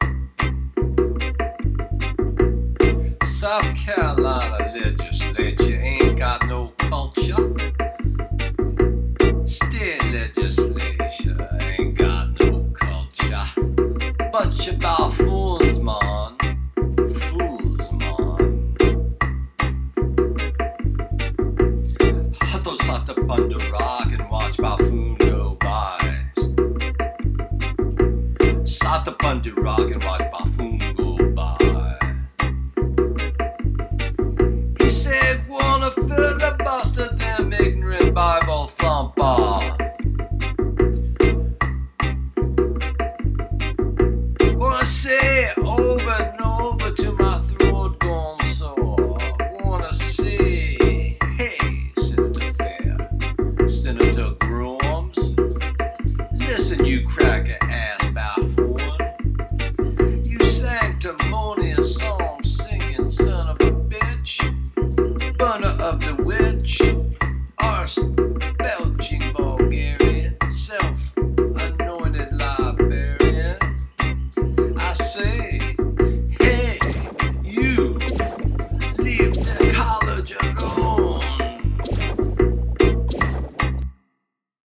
Senator, this dub poem is dedicated to you.